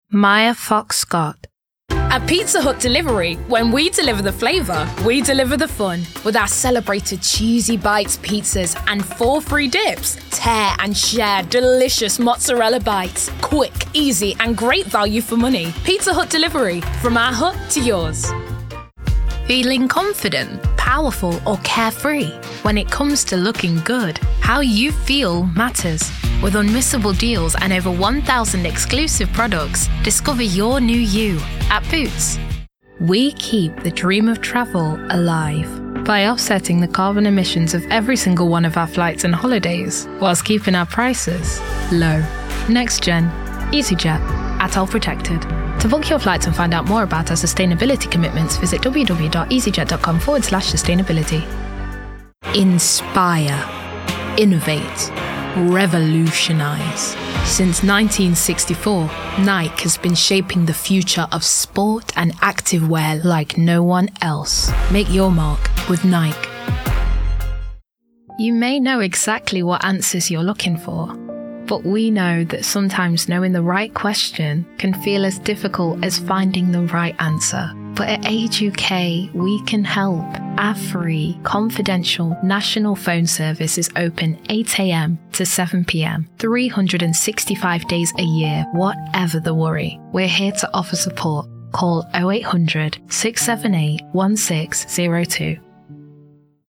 Commercial